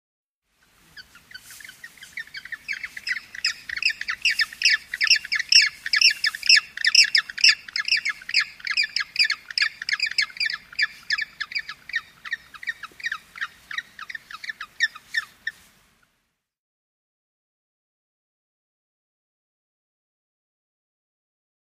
Penguin Chirps. Penguins Chirping With A Faint Beach Background And Some Plant Rustling. Medium Perspective.